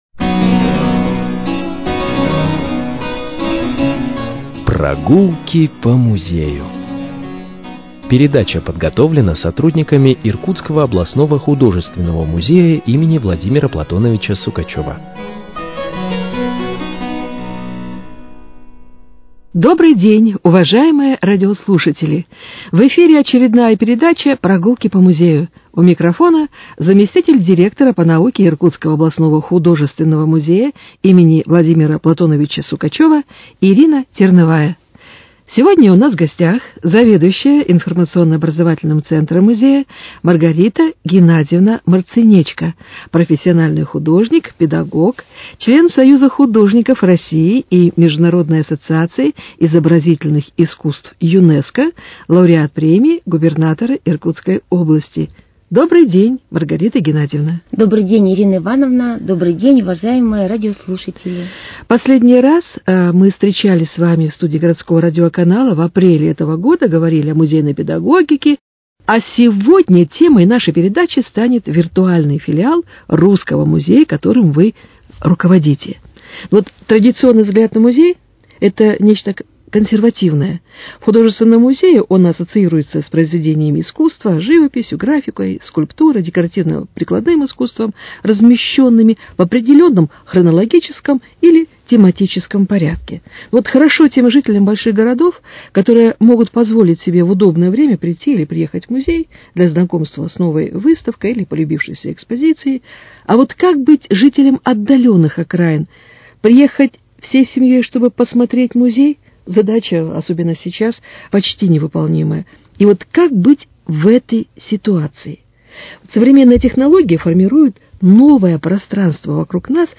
Передача
беседует